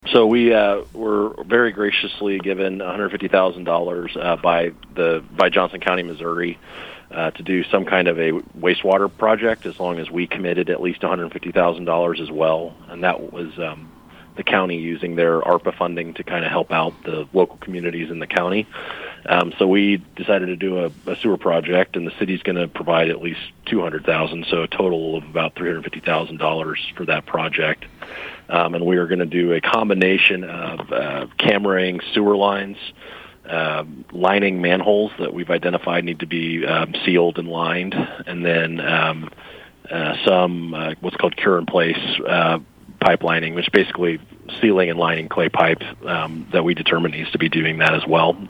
City Administrator Scott Peterson said the City received funding from Johnson County.